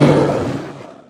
Minecraft Version Minecraft Version latest Latest Release | Latest Snapshot latest / assets / minecraft / sounds / mob / polarbear / death3.ogg Compare With Compare With Latest Release | Latest Snapshot